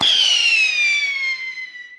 firework